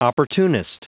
Prononciation du mot opportunist en anglais (fichier audio)
Prononciation du mot : opportunist